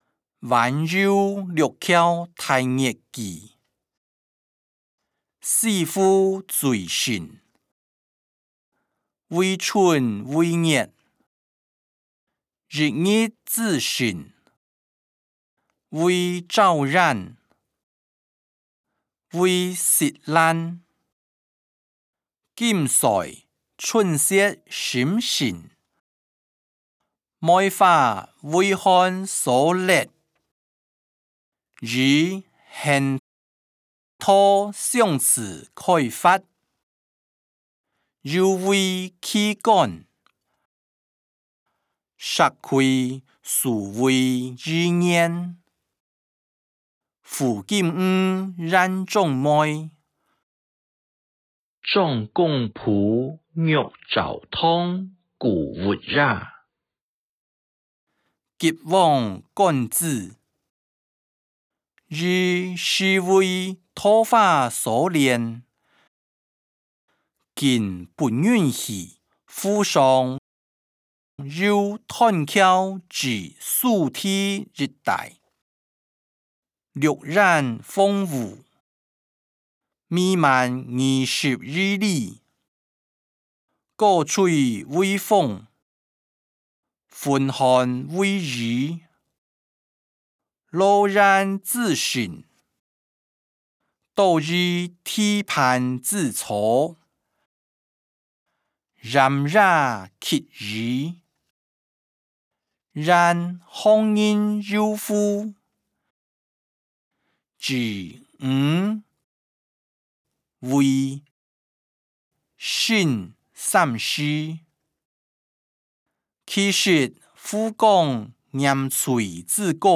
歷代散文-晚遊六橋待月記音檔(海陸腔)